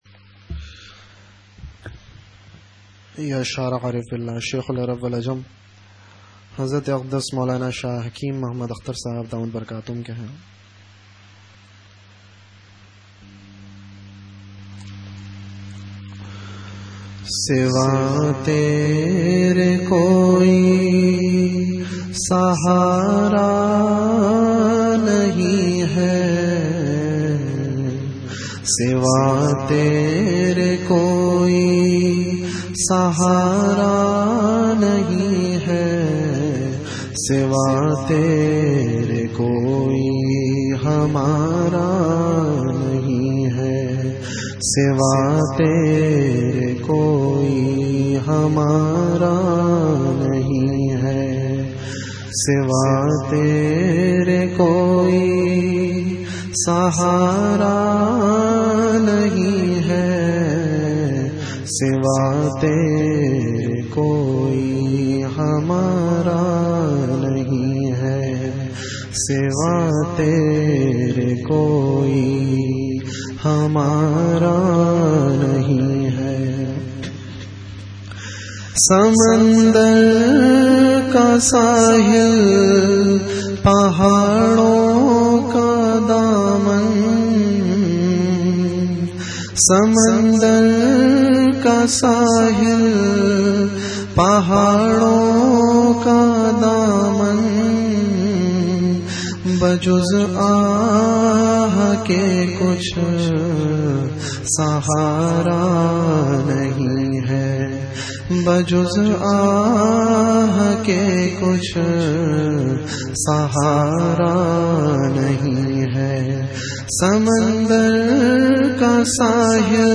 Category Majlis-e-Zikr
Venue Home Event / Time After Isha Prayer